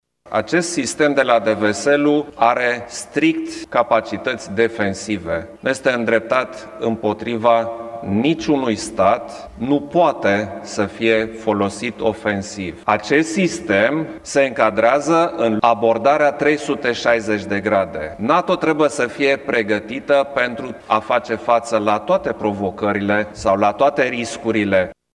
După întrevedere, cei doi oficiali au susținut o conferință de presă comună.